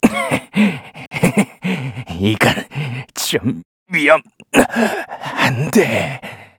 Kibera-Vox_Dead_kr_b.wav